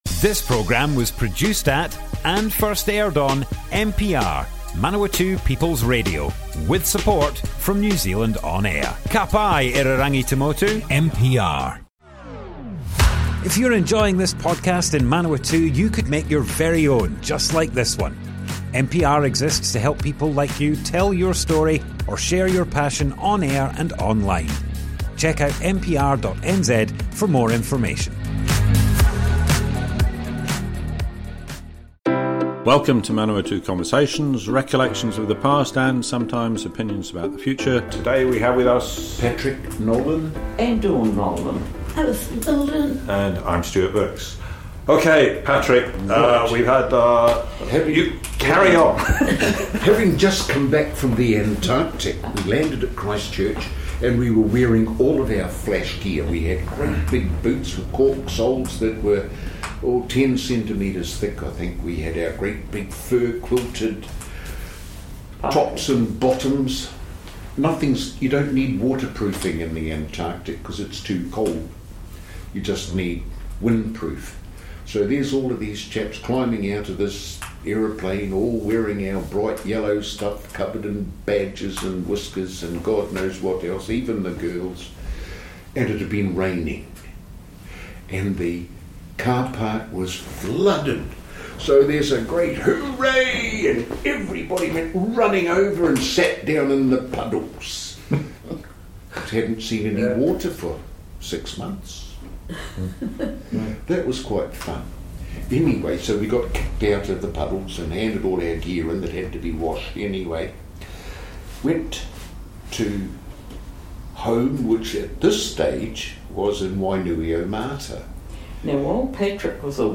Manawatu Conversations More Info → Description Broadcast on Manawatu People's Radio, 26th July 2022, Part 4 of 5.
oral history